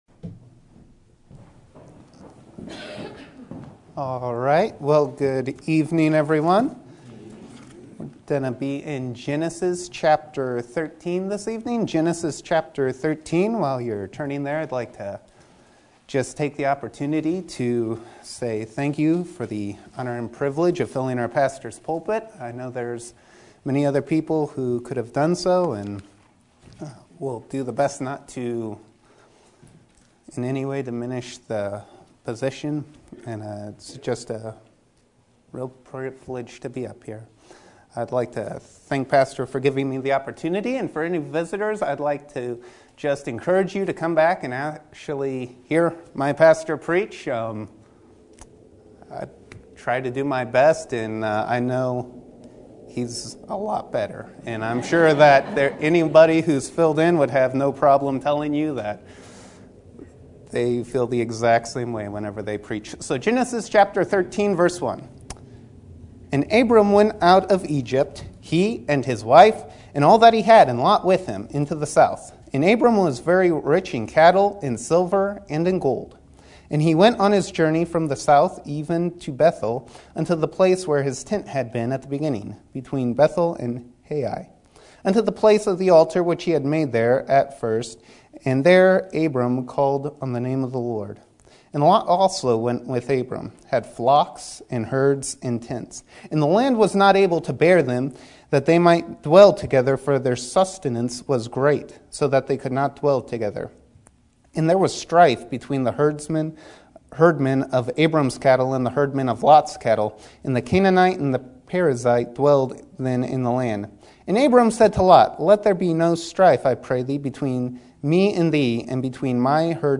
Sermon Topic: General Sermon Type: Service Sermon Audio: Sermon download: Download (27.14 MB) Sermon Tags: Genesis Compromise Sin Lot